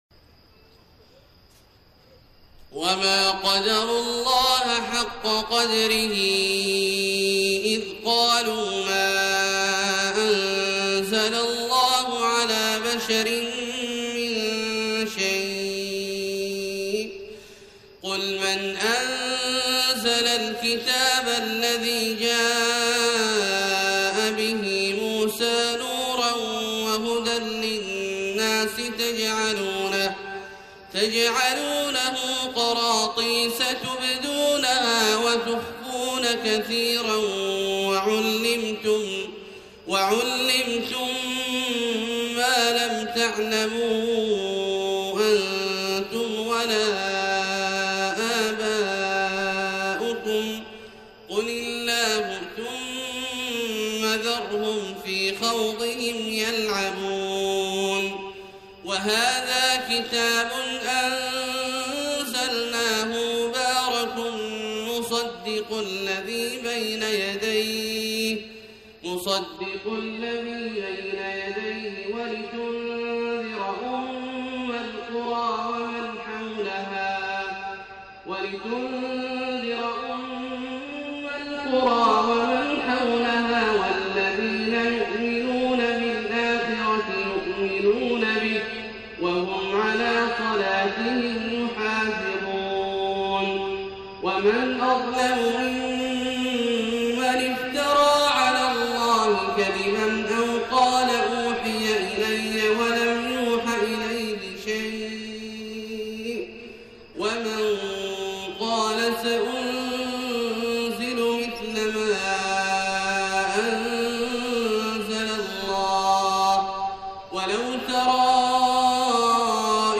صلاة الفجر 3-3-1431هـ من سورة الأنعام {91-110} > 1431 🕋 > الفروض - تلاوات الحرمين